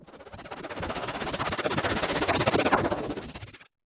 FLUTTERPAN.wav